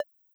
GenericButton6.wav